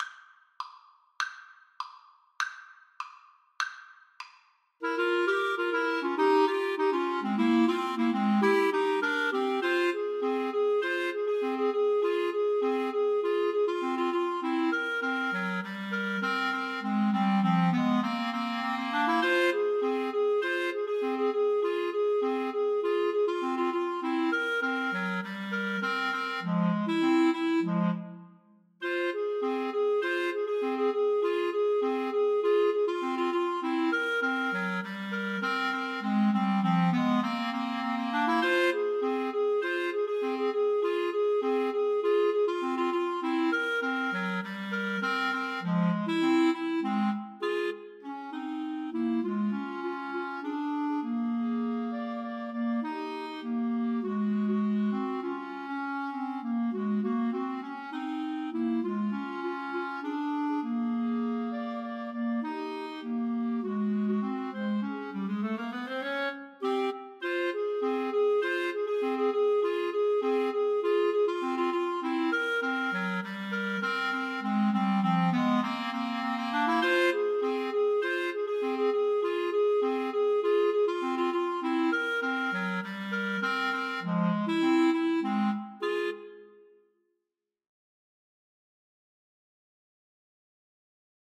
Clarinet 1Clarinet 2Clarinet 3
2/4 (View more 2/4 Music)
Allegro Moderato (View more music marked Allegro)
Pop (View more Pop Clarinet Trio Music)